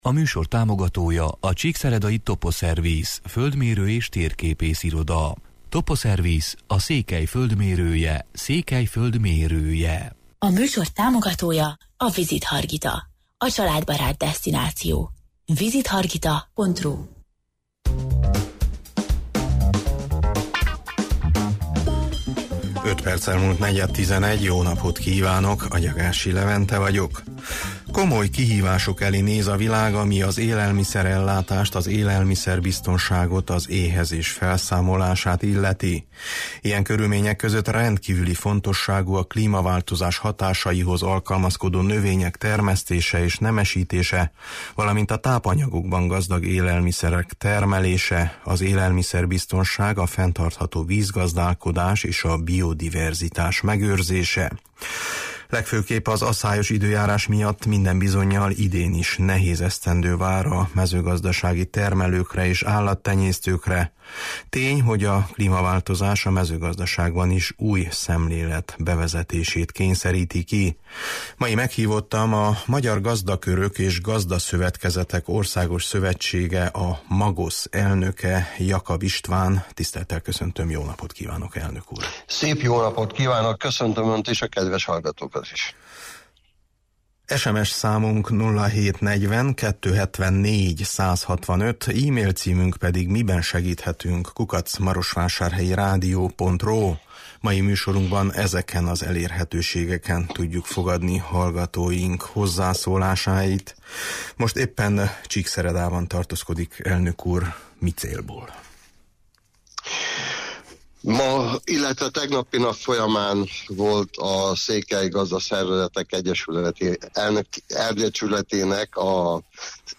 Masi meghívottam a Magyar Gazdakörök és Gazdaszövetkezetek Országos Szövetsége, a MAGOSZ elnöke, Jakab István: